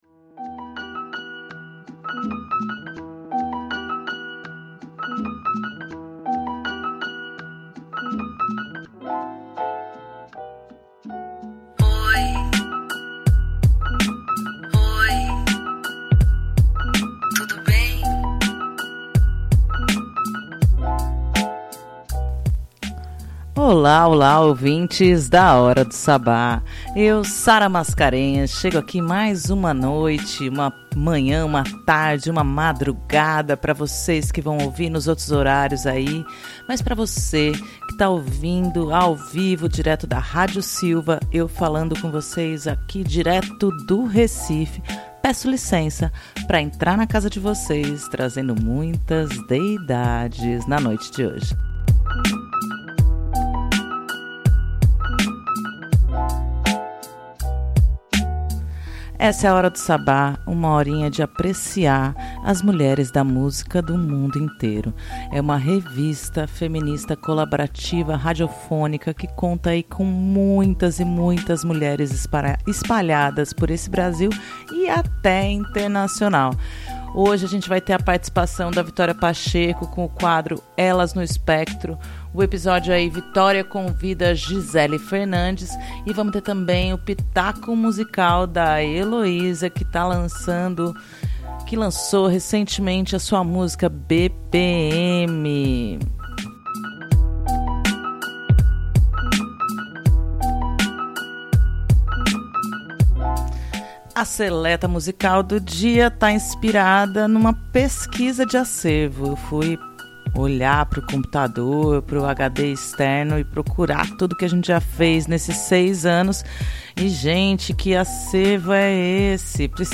A edição semanal da Hora do Sabbat traz uma seleta musical do acervo que está bárbara e fantástica!